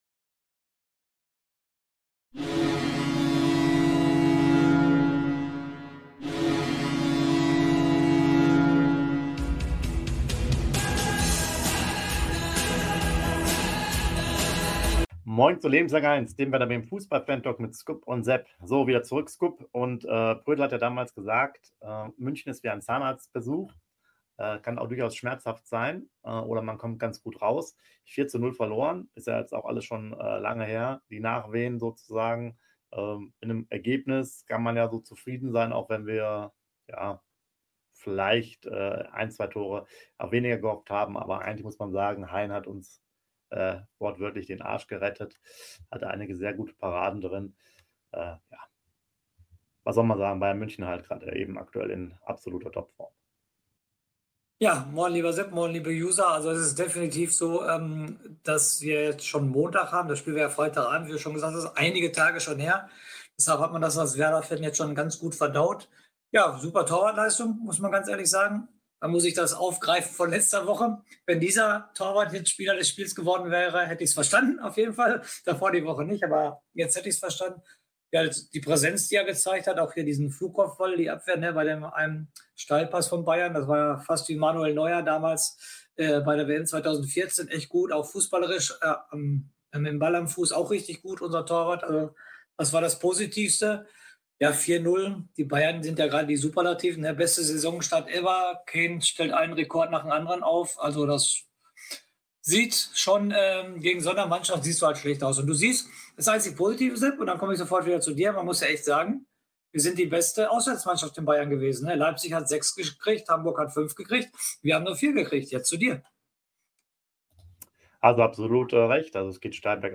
Dem Werder Bremen - Fantalk